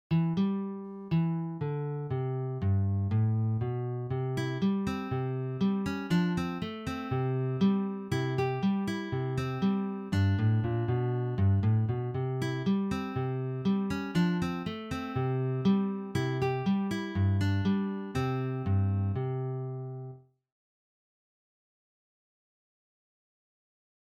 Für eine Gitarre
Jazz/Improvisierte Musik
Sololiteratur
Gitarre (1)